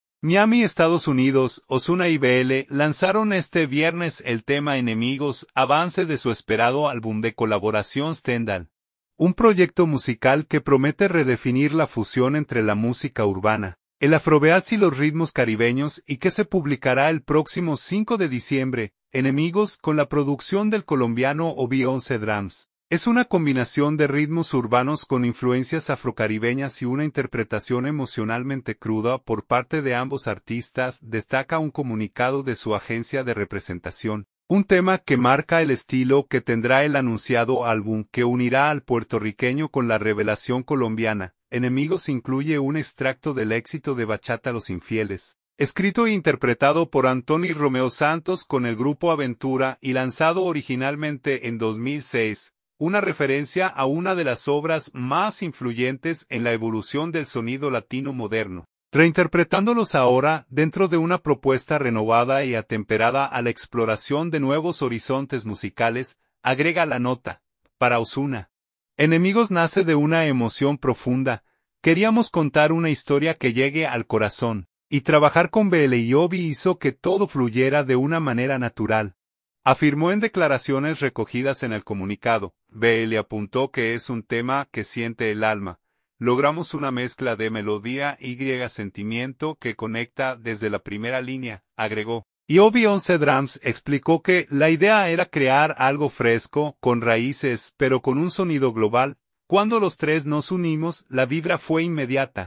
incluye un extracto del éxito de bachata